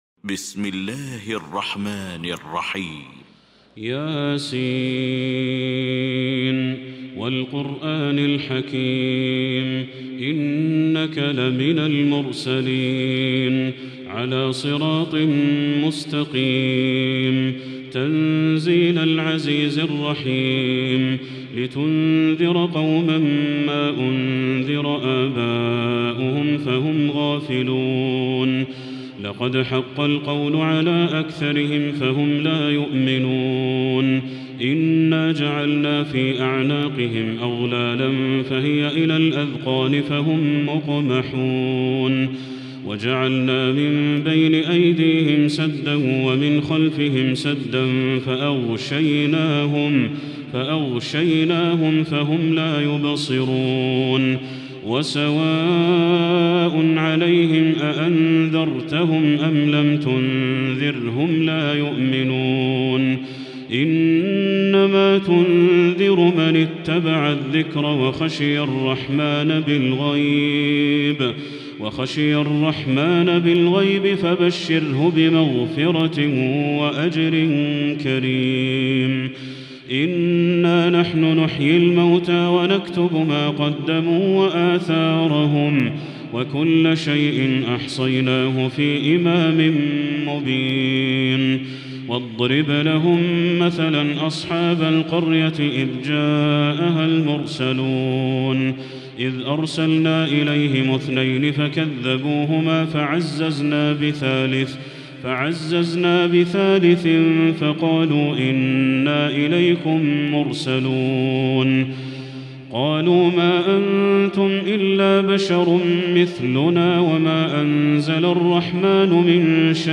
المكان: المسجد الحرام الشيخ